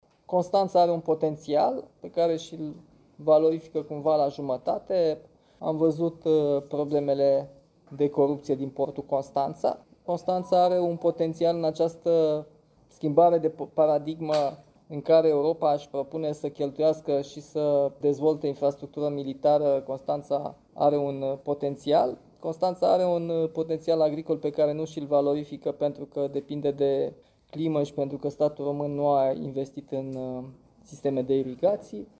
Prezent astăzi, la Constanța, la o întâlnire cu susținătorii, primarul general al Capitalei a vorbit despre problemele cu care se confruntă România.